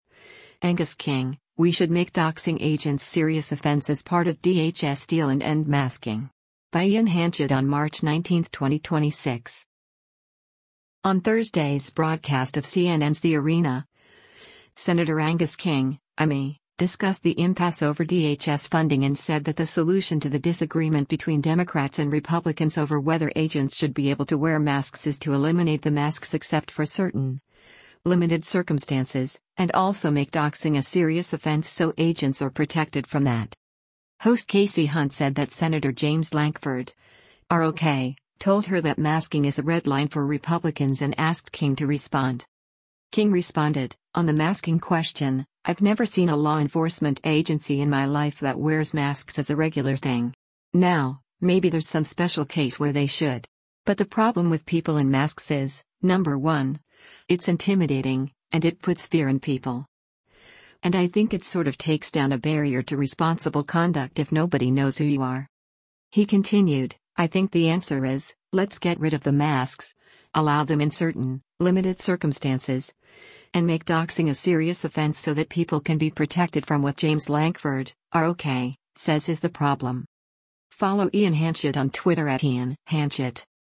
On Thursday’s broadcast of CNN’s “The Arena,” Sen. Angus King (I-ME) discussed the impasse over DHS funding and said that the solution to the disagreement between Democrats and Republicans over whether agents should be able to wear masks is to eliminate the masks except for “certain, limited circumstances,” and also “make doxxing a serious offense” so agents are protected from that.
Host Kasie Hunt said that Sen. James Lankford (R-OK) told her that masking is a red line for Republicans and asked King to respond.